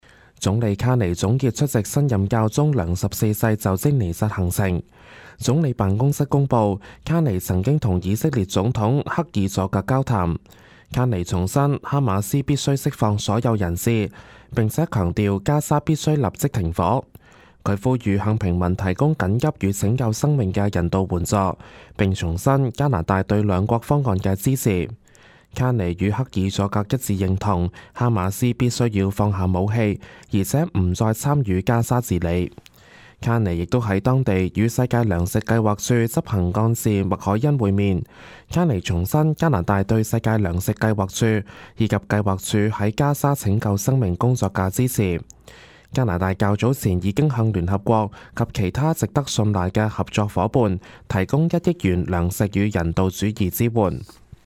Canada/World News 全國/世界新聞
news_clip_23468.mp3